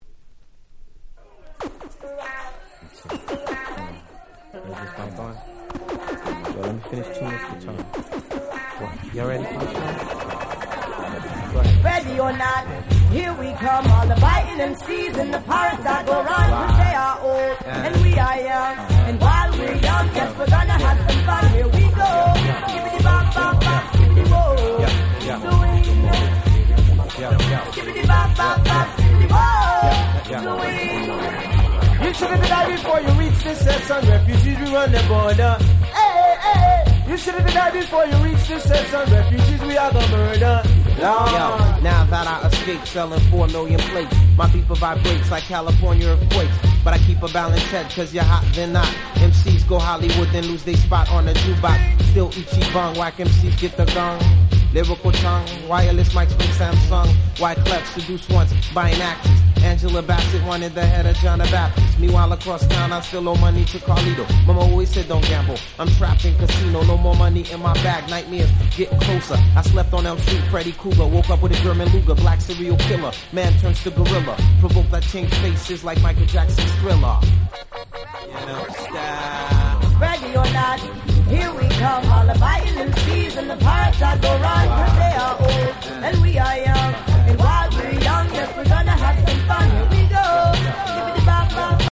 96' Super Hit R&B !!